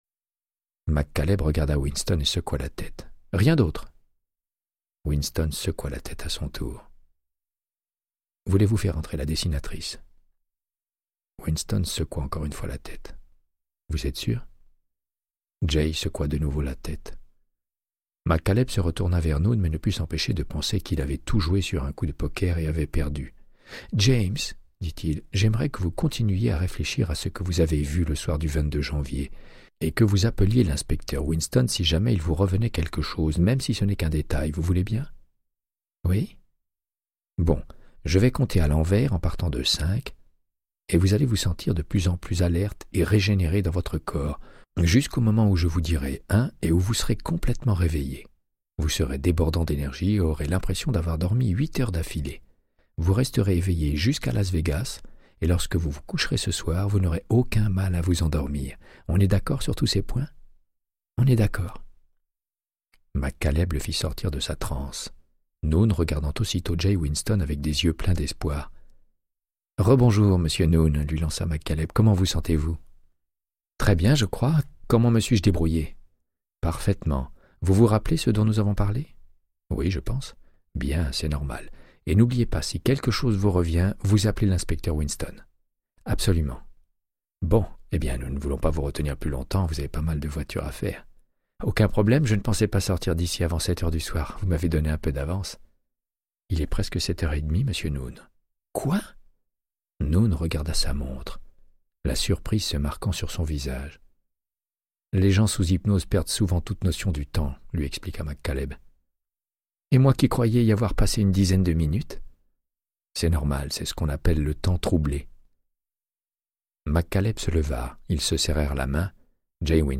Audiobook = Créance de sang, de Michael Connellly - 71